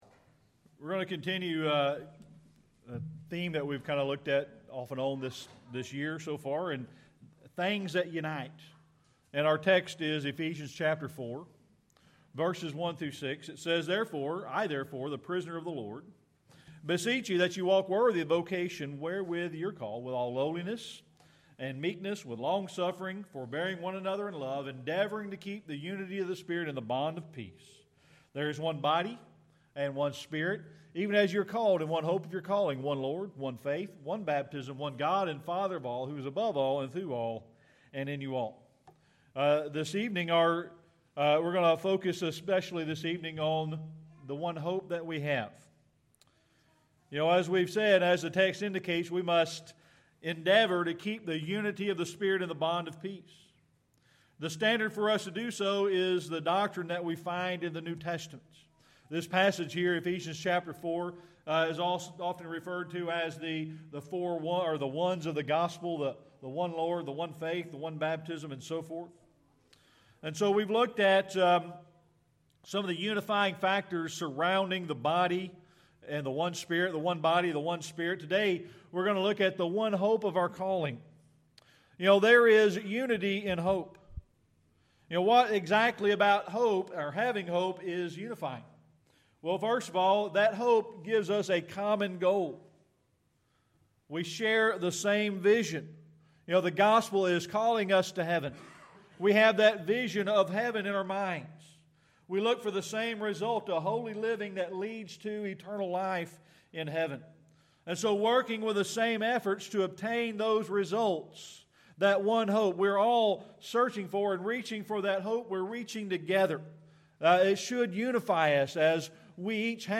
Ephesians 4:1-6 Service Type: Sunday Evening Worship We're going to continue in our discussion of things that unite